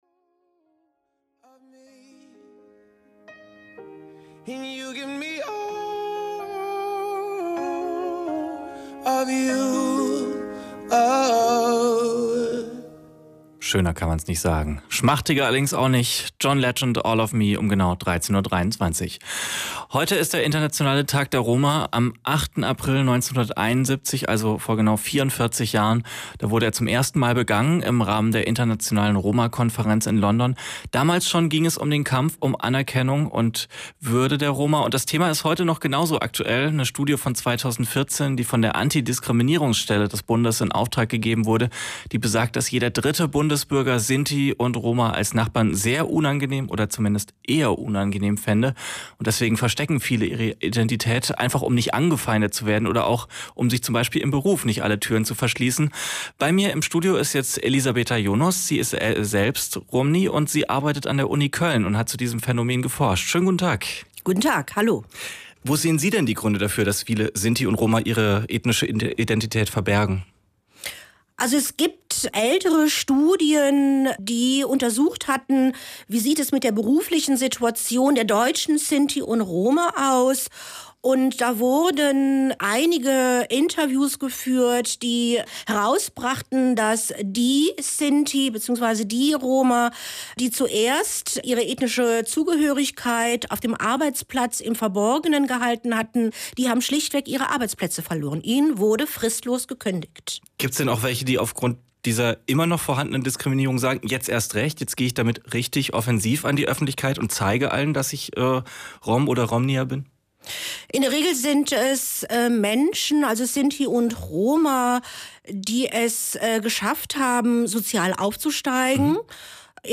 Funkhaus Eurpa: Interview